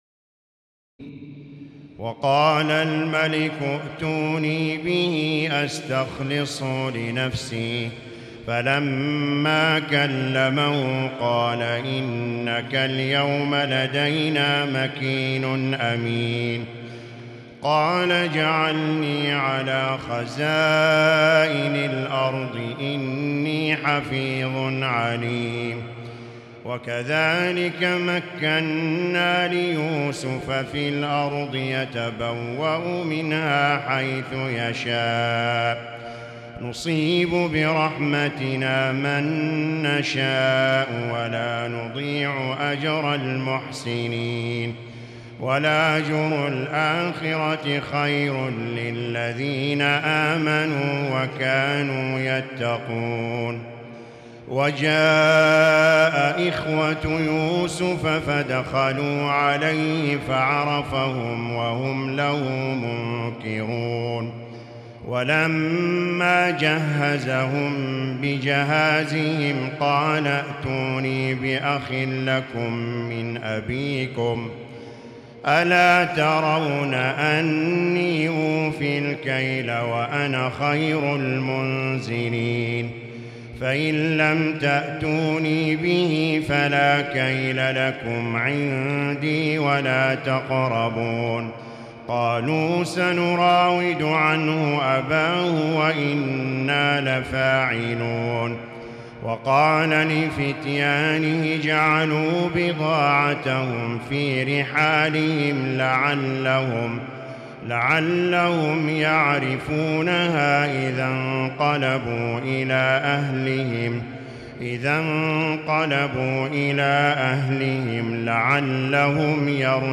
تراويح الليلة الثانية عشر رمضان 1438هـ من سورتي يوسف (54-111) و الرعد (1-18) Taraweeh 12 st night Ramadan 1438H from Surah Yusuf and Ar-Ra'd > تراويح الحرم المكي عام 1438 🕋 > التراويح - تلاوات الحرمين